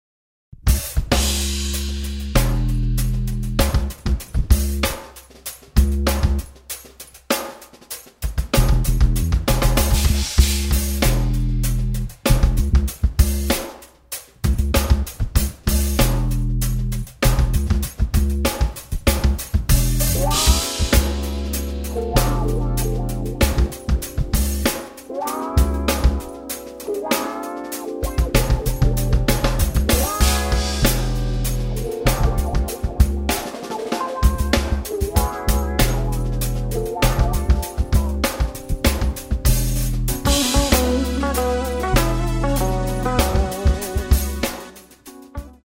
electric bass, keyboards & programming on track 6
drums on tracks 2 & 7
fretted and fretless guitars on tracks 2 & 7
keyboards on tracks 2, 5, 7 & 8